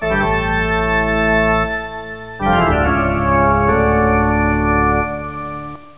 organ.wav